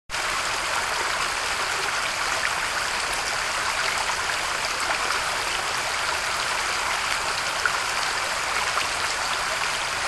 HanoverStream.mp3